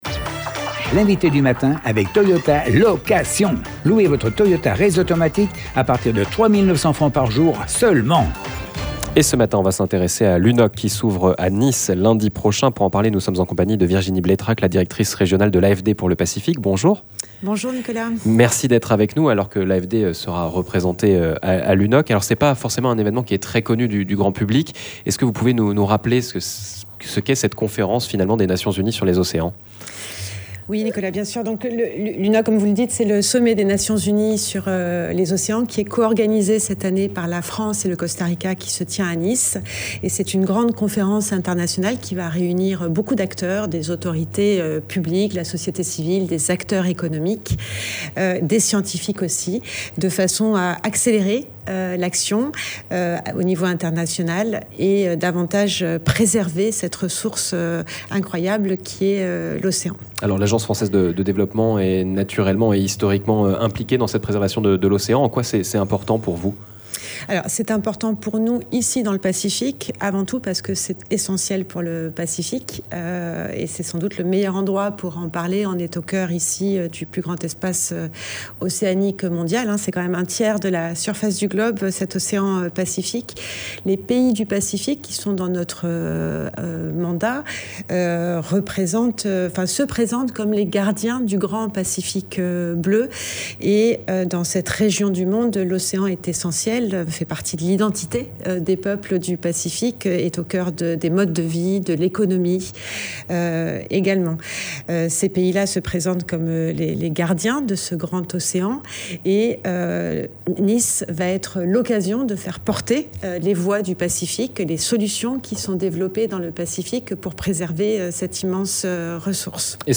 L'INVITÉ DU MATIN